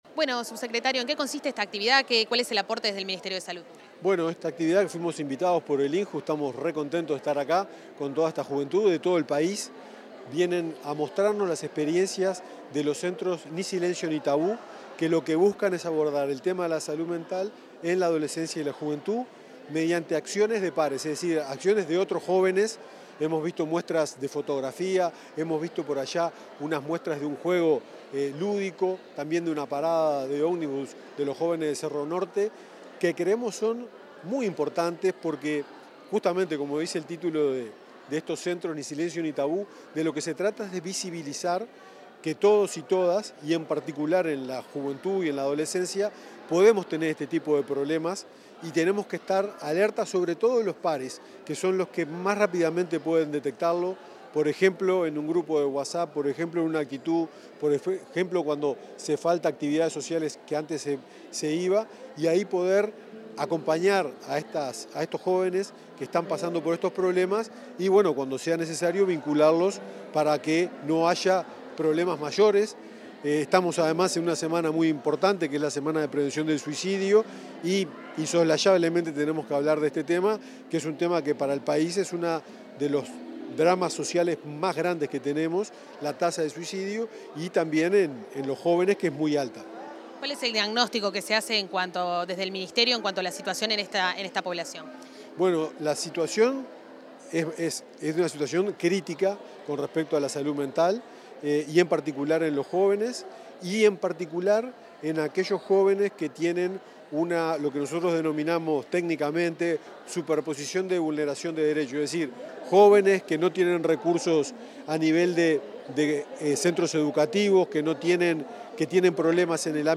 Declaraciones del subsecretario de Salud Pública, Leonel Briozzo